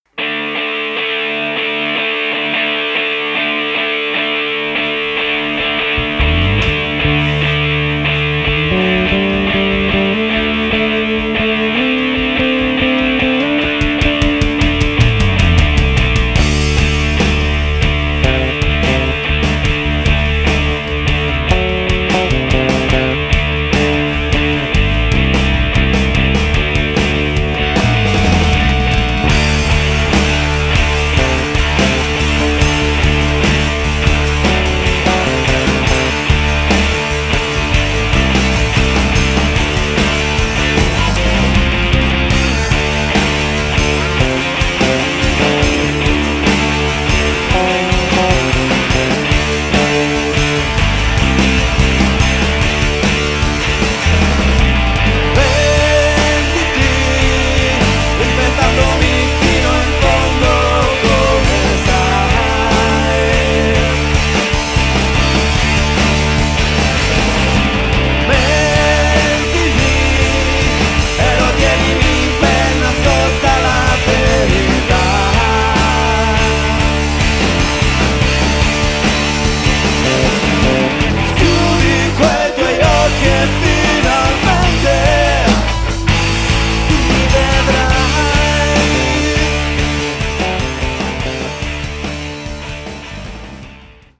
Genere: Rock.